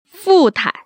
[fù‧tai] 푸타이